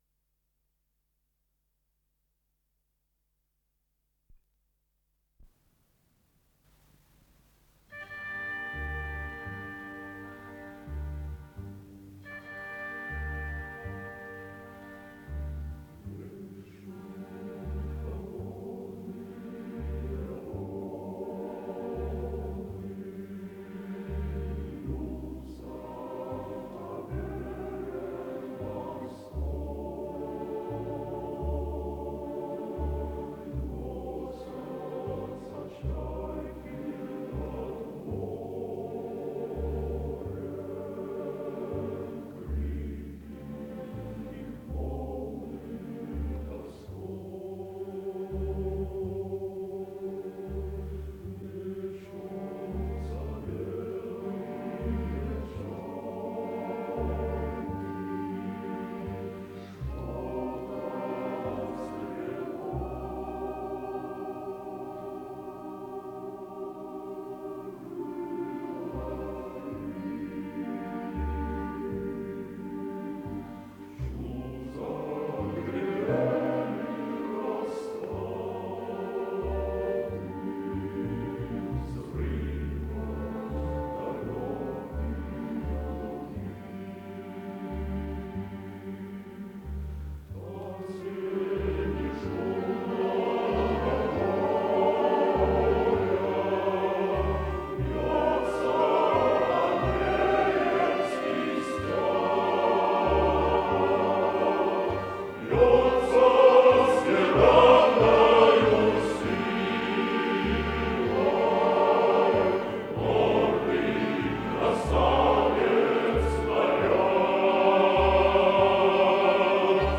с профессиональной магнитной ленты
ИсполнителиАнсамбль песни и пляски Киевского военного округа
Дирижёр - Иван Карабиц
ВариантДубль моно